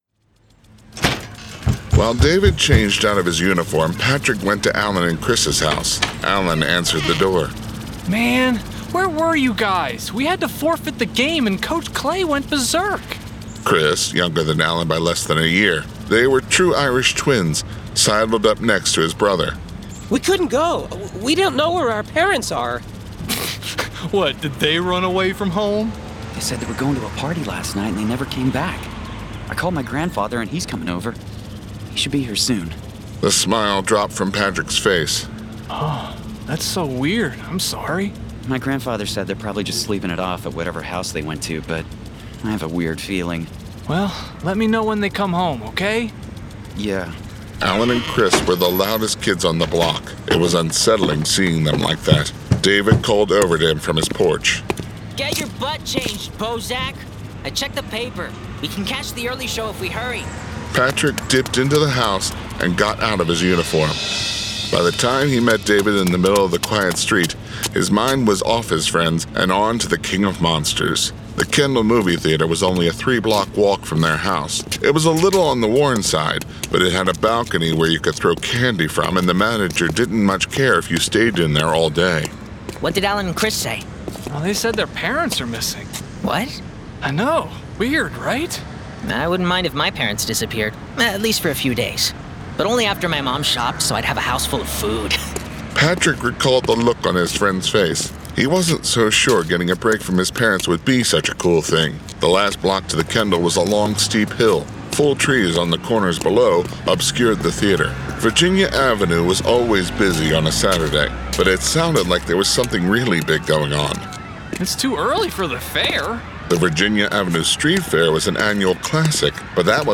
Full Cast. Cinematic Music. Sound Effects.
[Dramatized Adaptation]
Genre: Horror